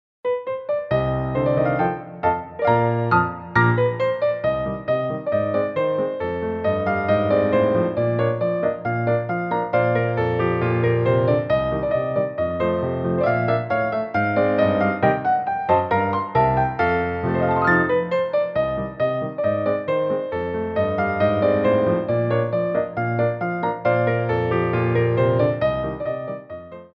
Medium Allegro 1
2/4 (16x8)